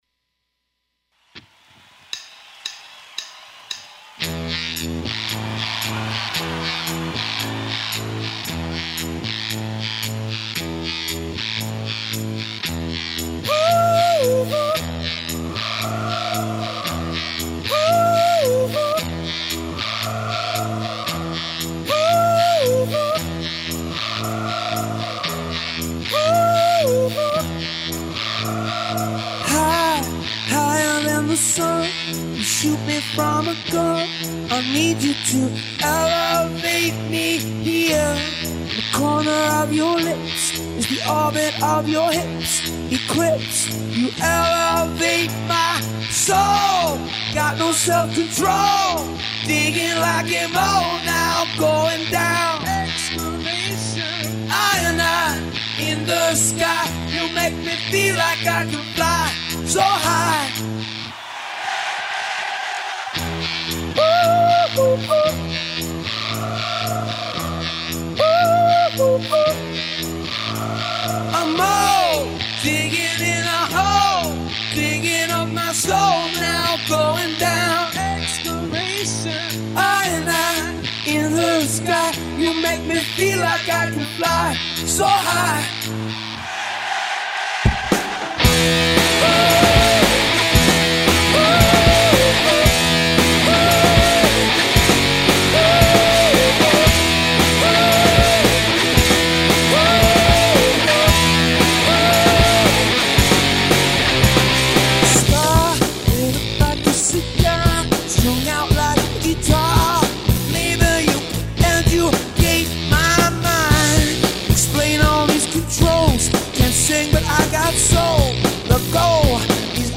Multi-track recording